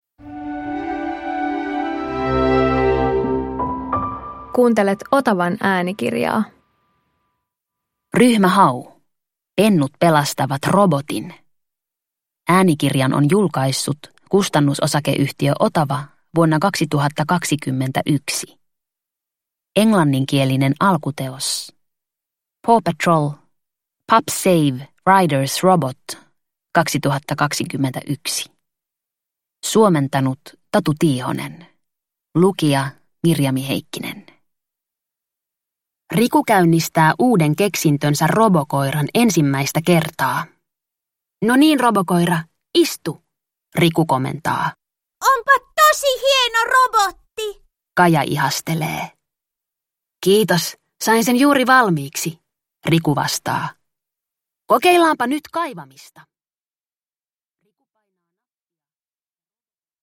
Ryhmä Hau - Pennut pelastavat robotin – Ljudbok – Laddas ner